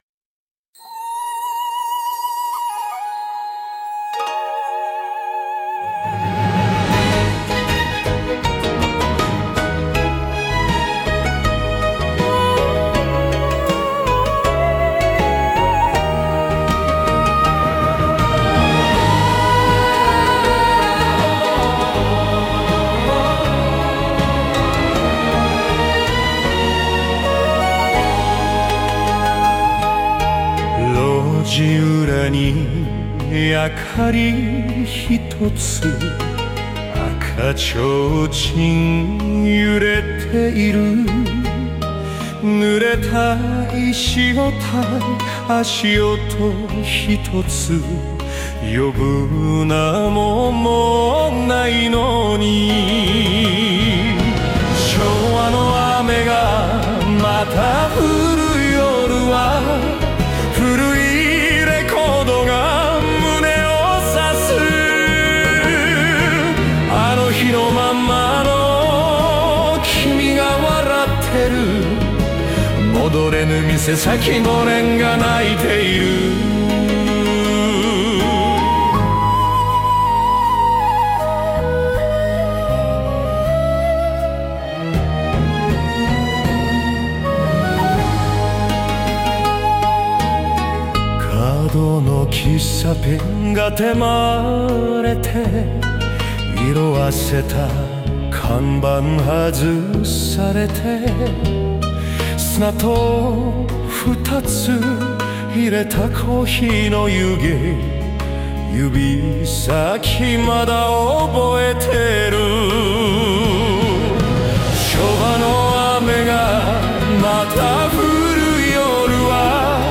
2:59 演歌風 尺八、三味線バラード「昭和の雨が降る夜」